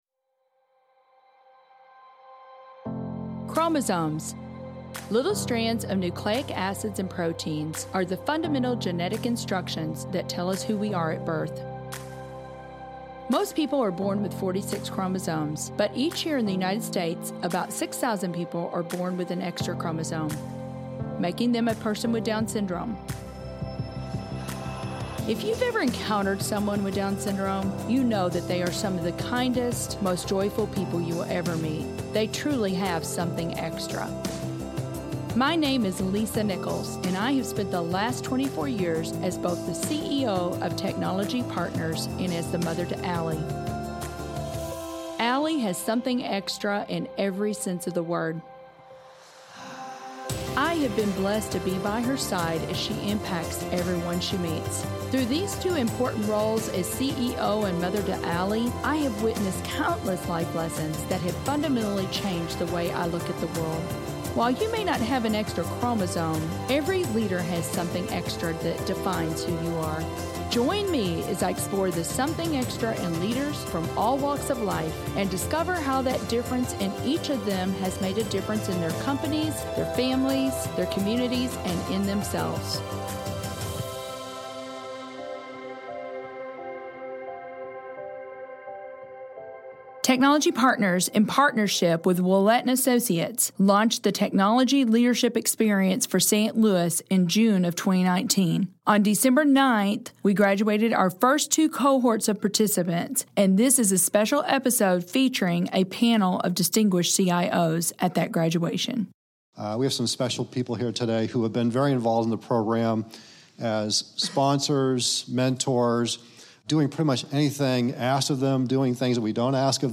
Tech LX Live Panel w/ Something Extra
In a special episode of the Something Extra podcast, we welcome four C-suite level IT leaders at the graduation of Technology Partners and Ouellette and Associates leadership development program, Tech LX.